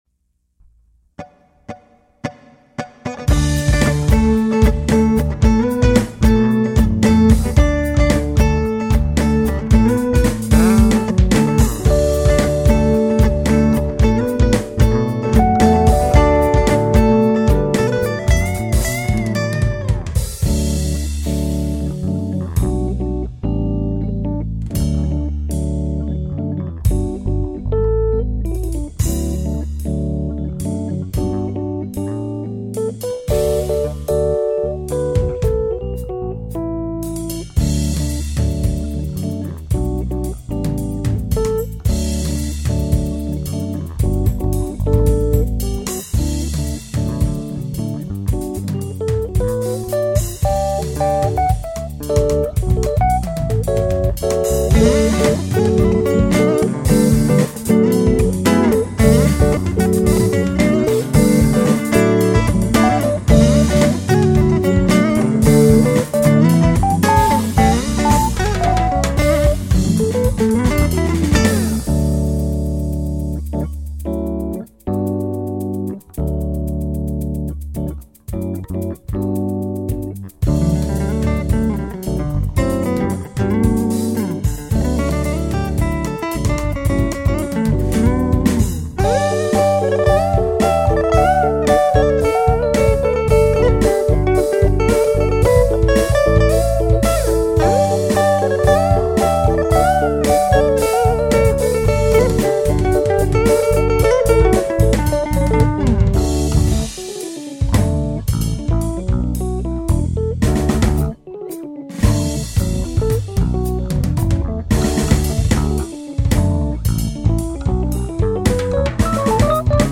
Guitars, bass, and rhodes piano
Drums programmed